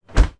Звуки морозильной камеры
Звук захлопнувшейся дверцы домашнего морозильника